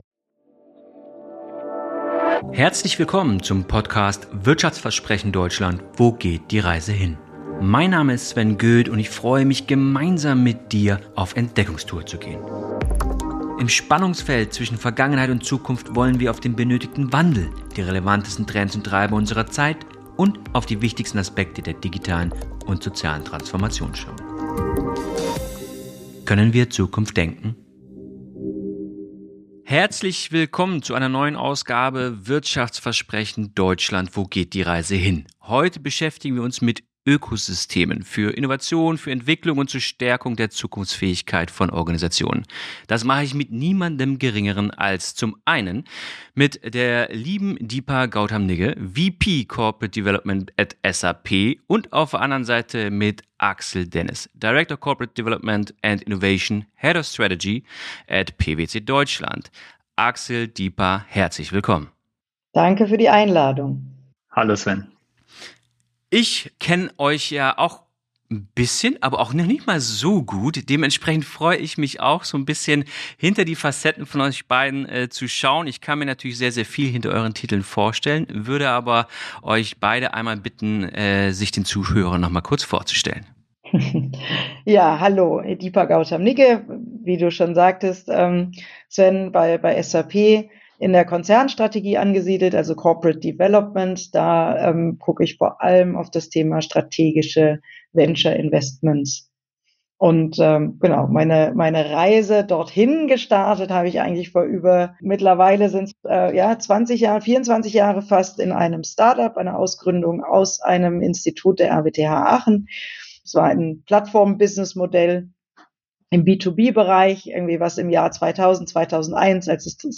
Freut Euch auf ein fesselndes Gespräch, das theoretische Ansätze mit praktischen Erkenntnissen verbindet, um die wahren Potenziale und Herausforderungen hinter der Thematik zu enthüllen.